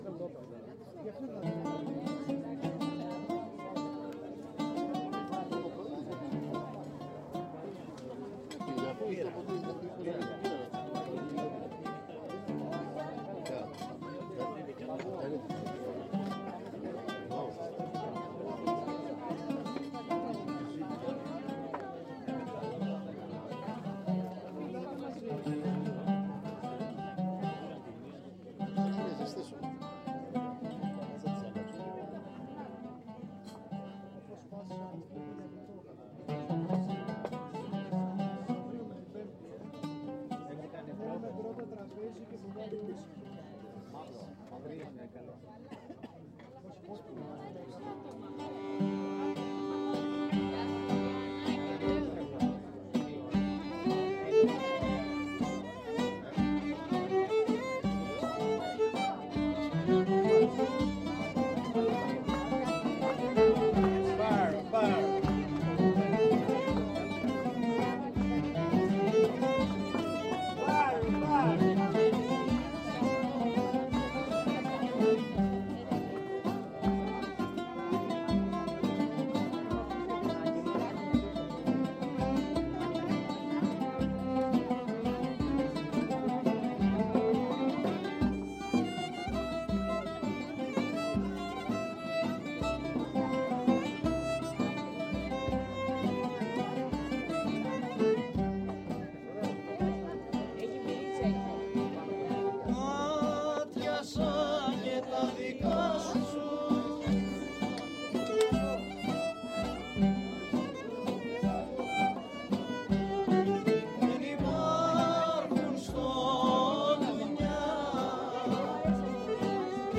Tarab, un groupe venu de Chypre, joue sous les oliviers de la taverne de Halki. Les villageois réunis boivent, rient, oublient leurs soucis en enchaînant les sirtakis.